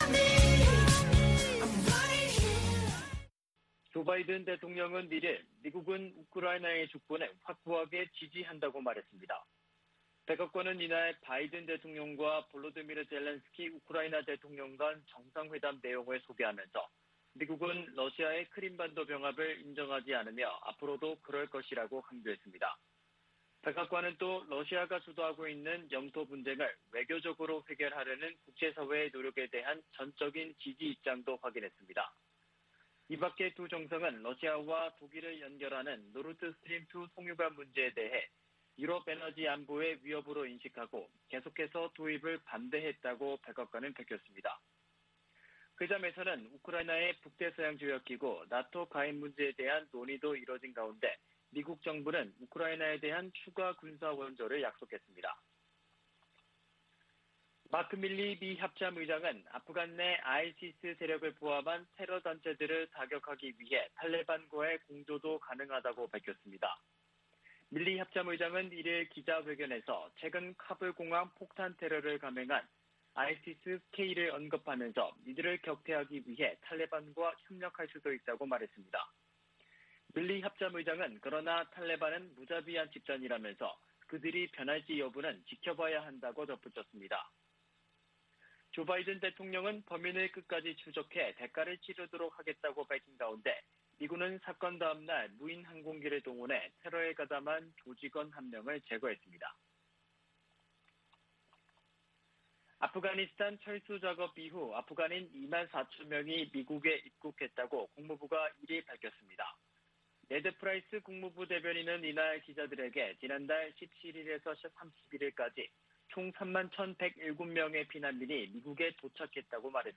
VOA 한국어 아침 뉴스 프로그램 '워싱턴 뉴스 광장' 2021년 9월 3일 방송입니다. 미 국무부가 미국인들의 북한 여행금지 조치를 1년 더 연장하기로 했습니다. 미국의 구호 단체들과 이산가족 단체들은 북한 여행금지 재연장에 실망을 표시했습니다.